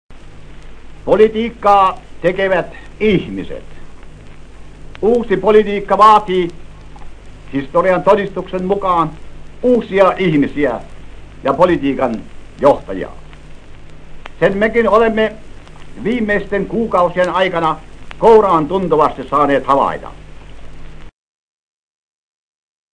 Die Rede ist aus dem Jahre 1928 und war eine Eröffnungsrede für den neuen Radiosender Antenna. Es ist die einzige existierende Aufnahme von Präsident Relanders Reden.